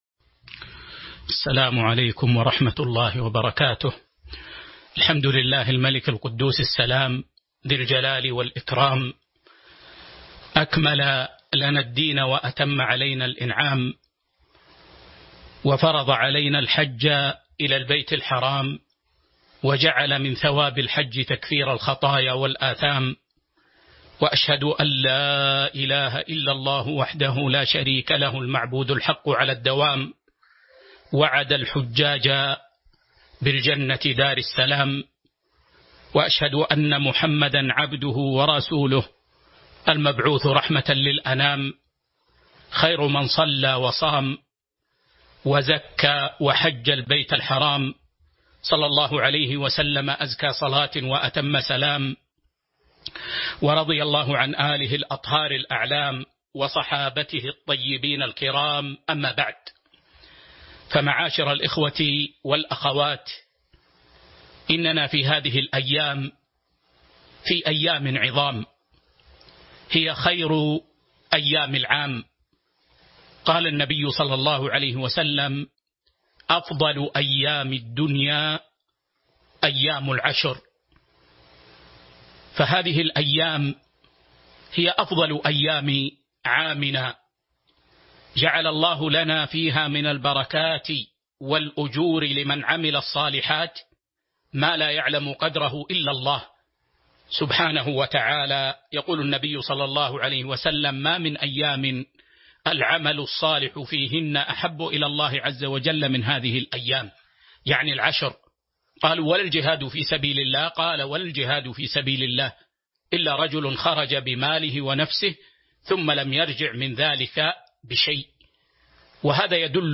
المكان: المسجد النبوي